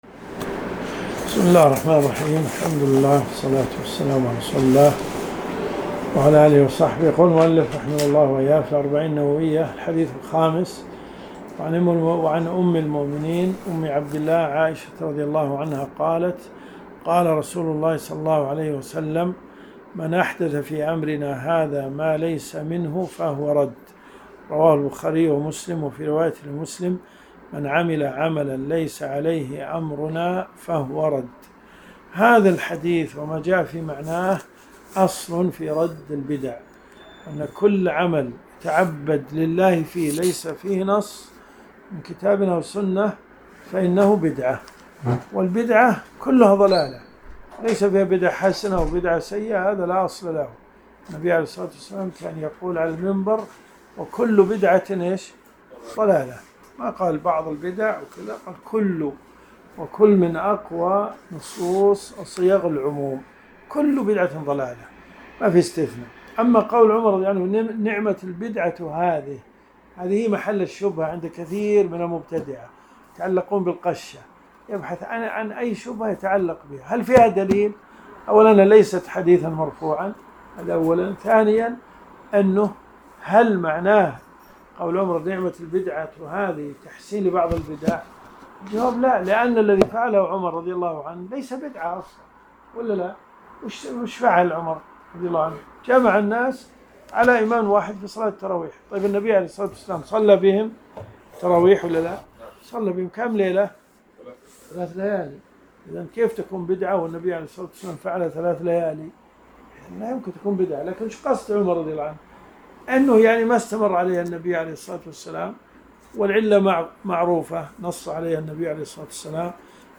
الرئيسية الدورات الشرعية [ قسم الحديث ] > الأربعون النووية . 1444 .